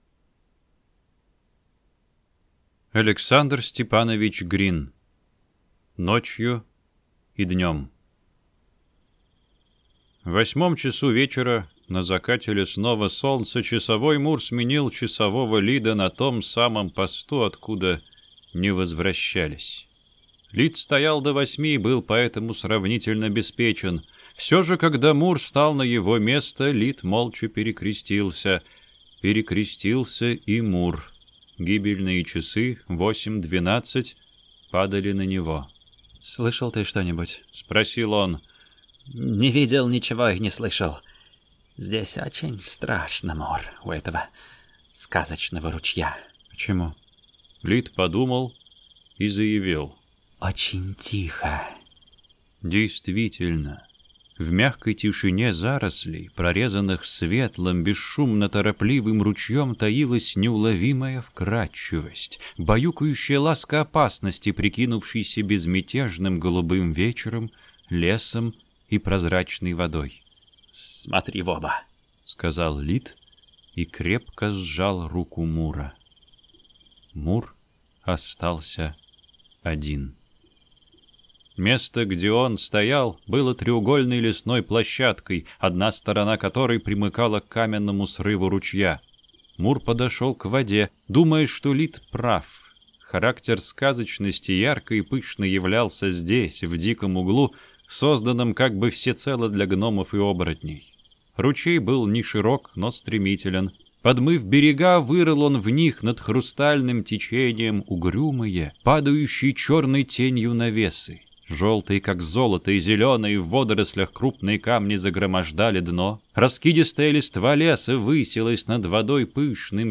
Аудиокнига Ночью и днем | Библиотека аудиокниг